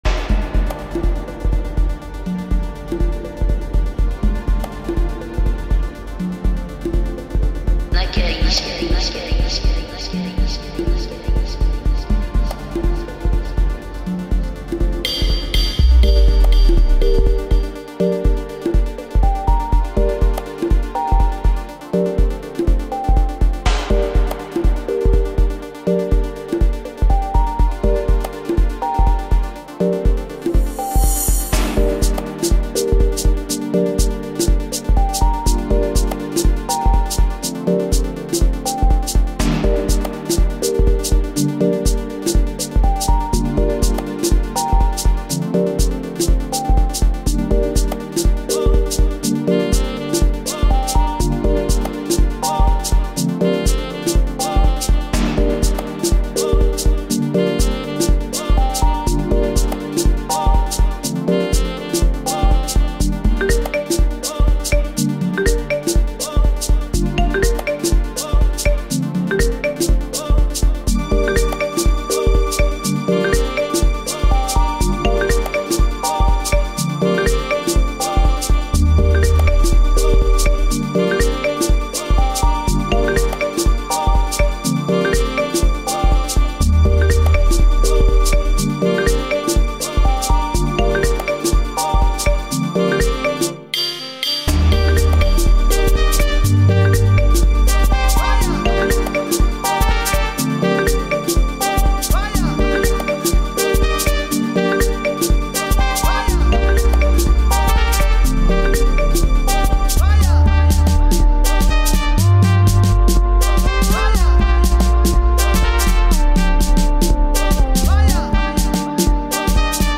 South African singer-songwriter